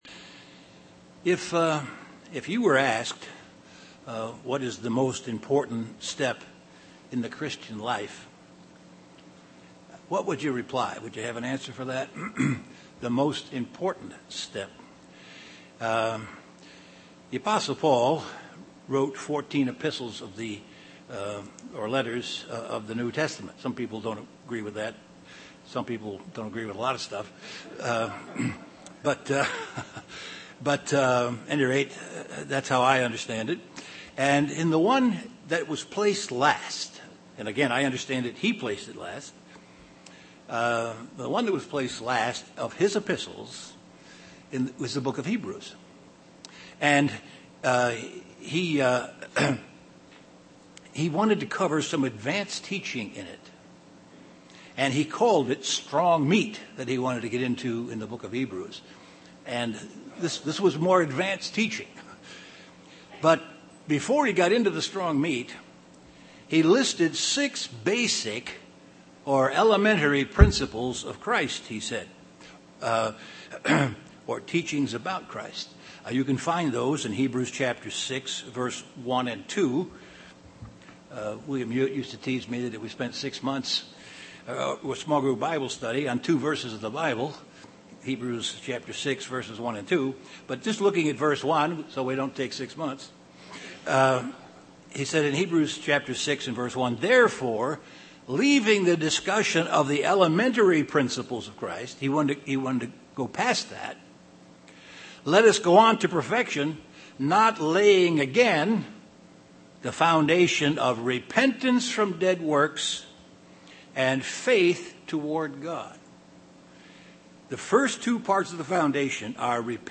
Given in Chicago, IL
UCG Sermon Studying the bible?